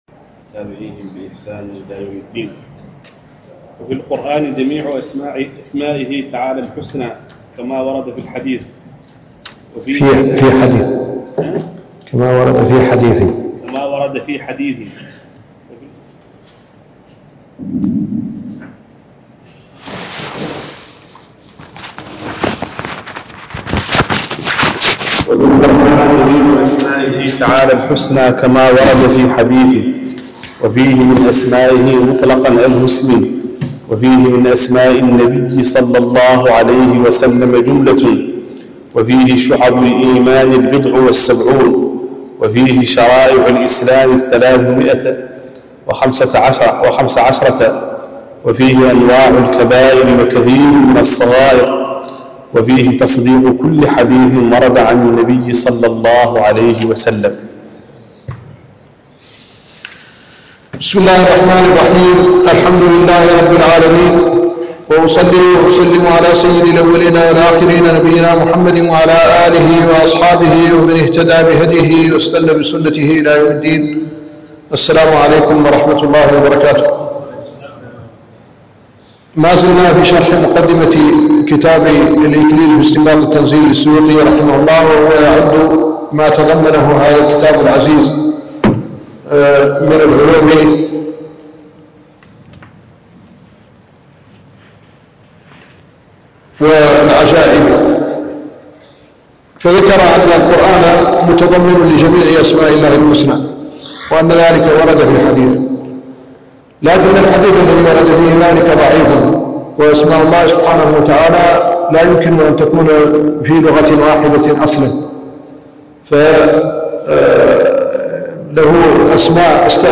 الدرس السادس عشر من شرح وتعليق الشيخ الددو على كتاب الإكليل في استنباط التنزيل - الشيخ محمد الحسن ولد الددو الشنقيطي